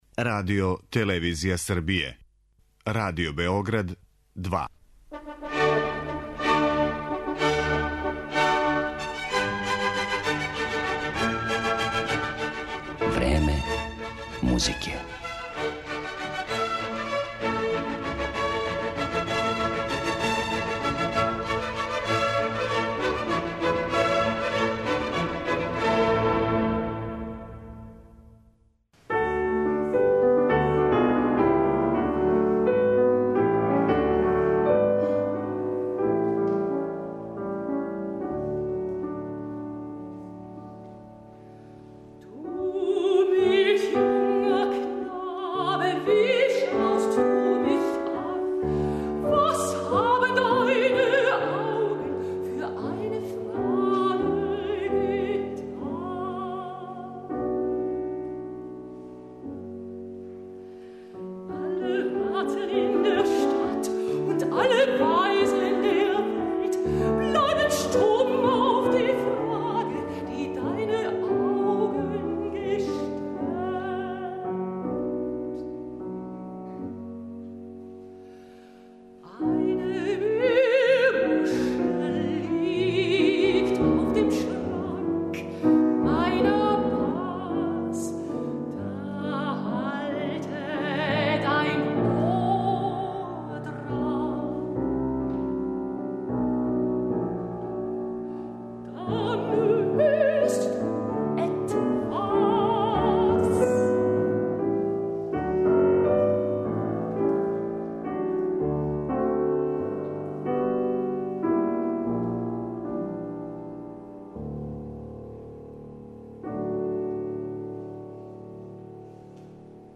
славни аустријски мецосопран Ангелика Кирхслагер ће певати романтичарски лид и композиције различитих аутора писане на исте текстове.
У изврсном извођењу ове велике солисткиње и британског пијанисте Сајмона Лепера, моћи ћете да чујете како су неке од најлепших стихова Гетеа, Хајнеа, Мерикеа, Шамисоа и Келера доживели и музички уобличили Јоханес Брамс, Хуго Волф, Франц Шуберт, Роберт Шуман и Франц Лист.